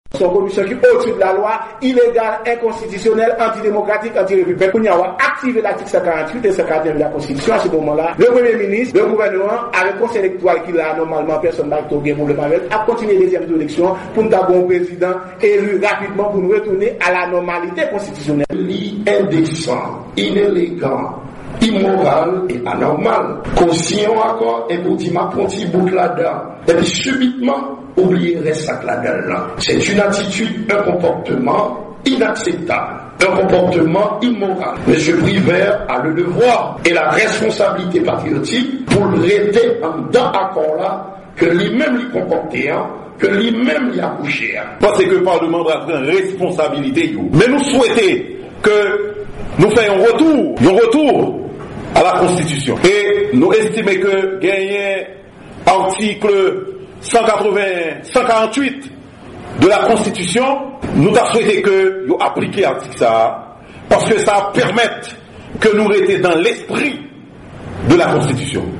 Deklarasyon lidè Pati Ayisyen Tèt Kale sou Komisyon Endepandan Evalyasyon ak Verifikasyon Elektoral la